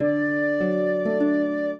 flute-harp
minuet15-11.wav